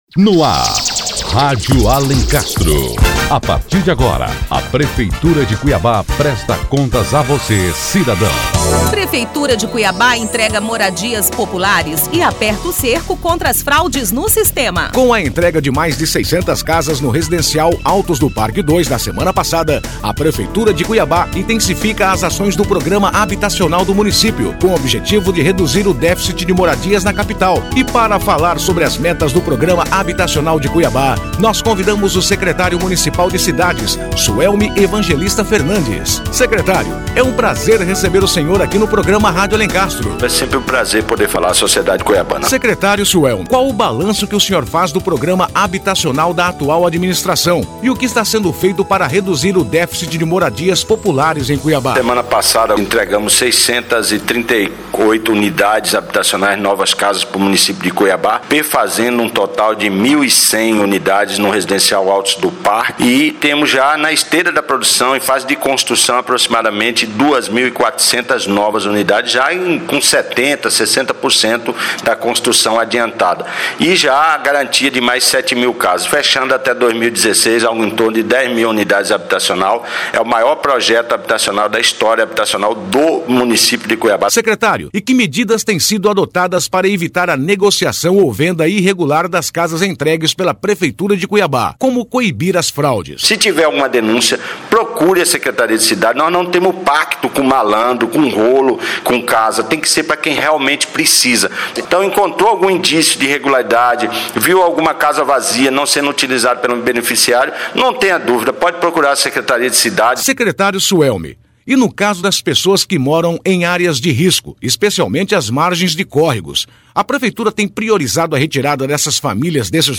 O Secretário Municipal de Cidades, Suelme Evangelista Fernandes, fala sobre as metas do Programa Habitacional da nossa capital.